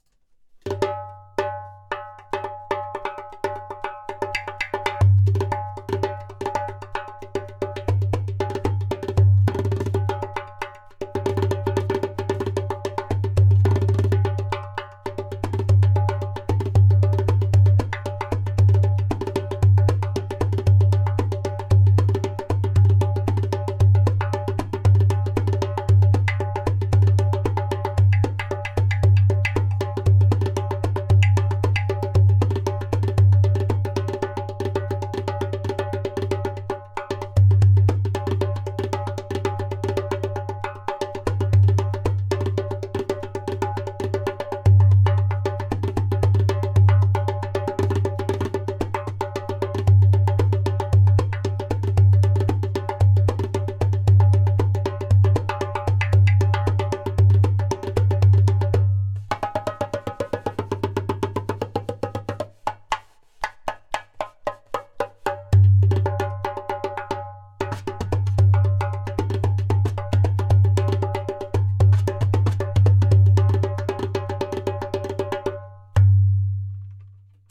115 bpm:
• Taks with harmonious overtones.
• Deep bass for a solo darbuka.
• Loud clay kik/click sound for a solo darbuka!